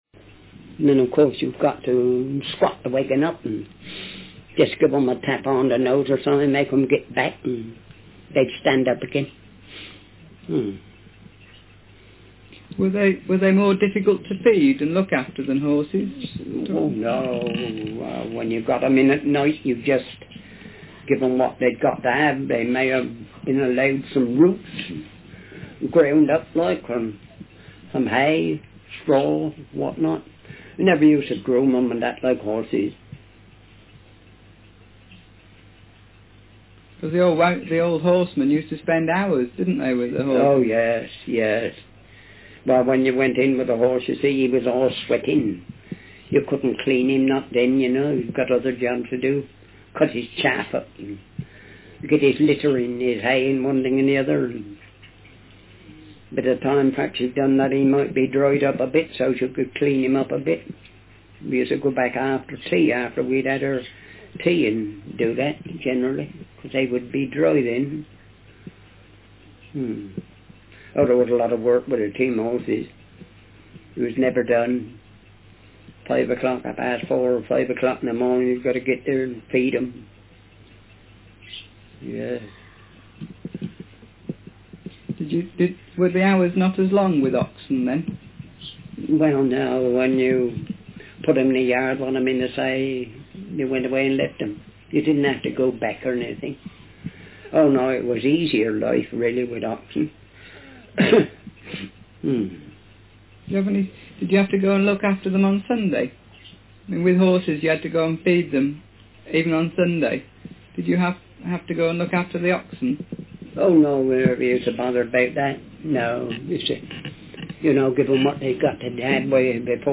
DoReCo - Language English (Southern England)
Speaker sex m Text genre personal narrative